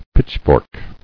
[pitch·fork]